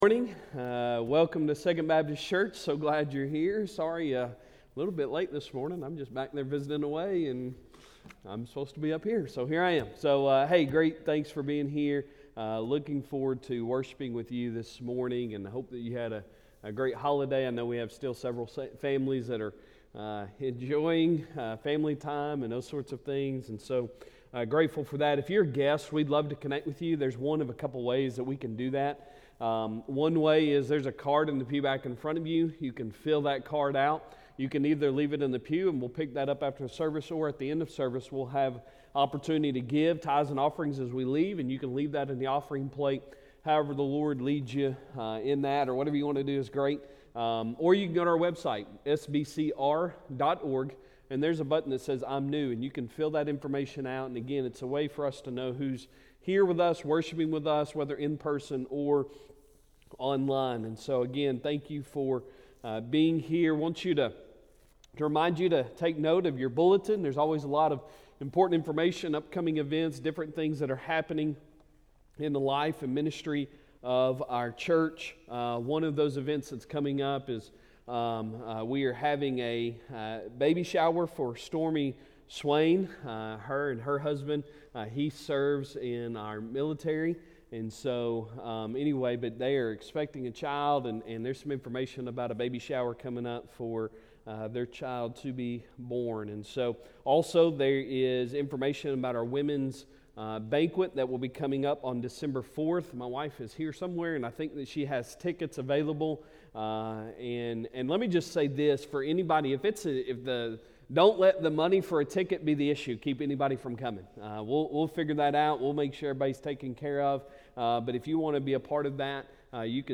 Sunday Sermon November 26, 2023